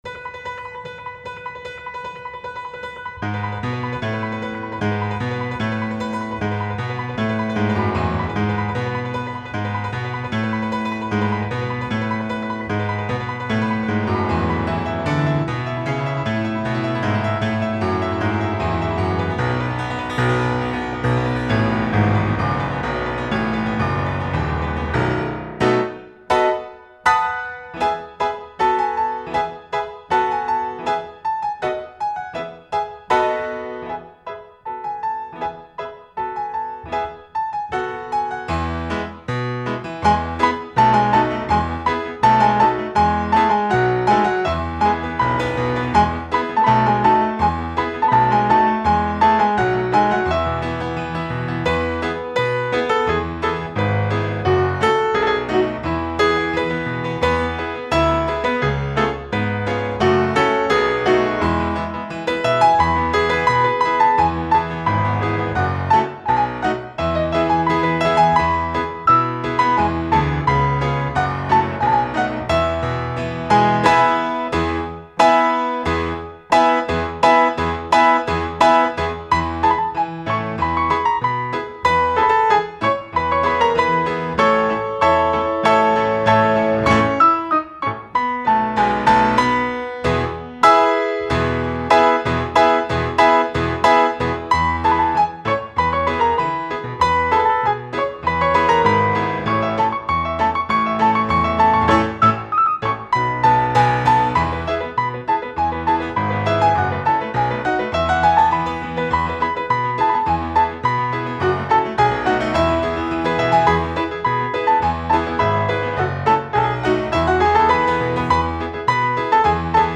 פסנתר
בכללי הוא מנגן שיר מהיר סולו אקורד וקצב בו זמנית!!!!
יש משהו במקצב של השיר בהתחלה שקצת צורם לי, אני צודק?